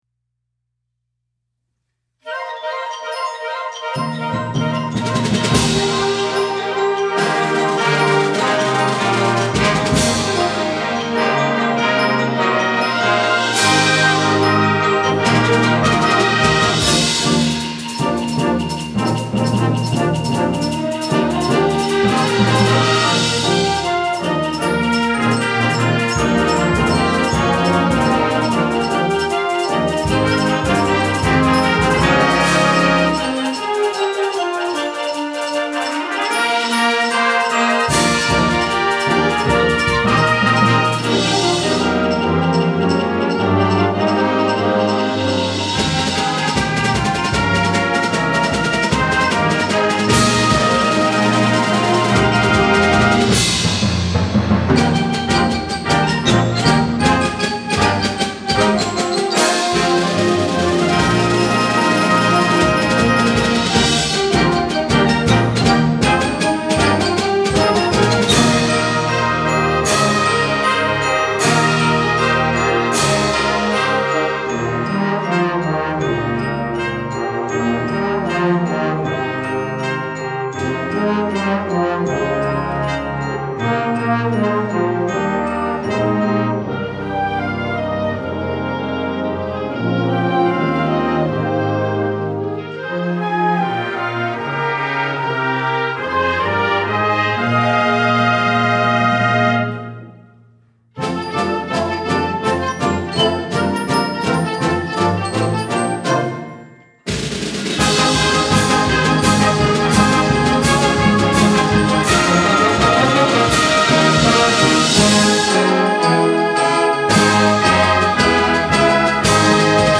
Winter Concert
--CONCERT BAND 1--